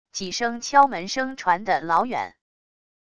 几声敲门声传的老远wav音频